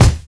Kick (Gas Drawls).wav